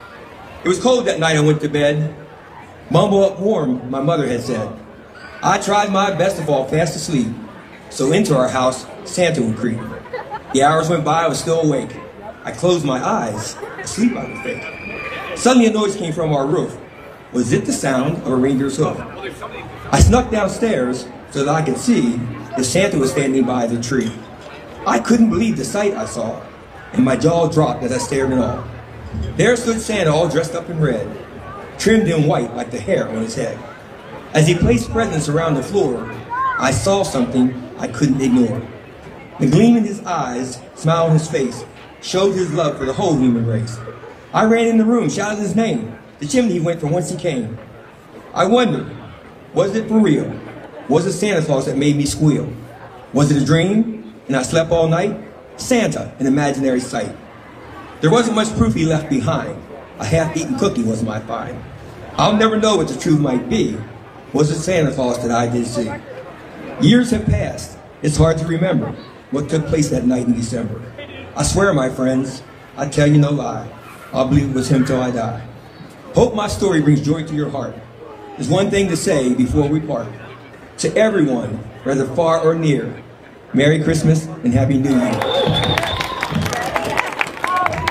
A chilly night in downtown Cumberland Friday as the community gathered to light the annual Christmas tree at the corner of Liberty and Baltimore Street. City Councilman, Eugene Frazier read aloud his holiday poem entitled a Christmas Wonder…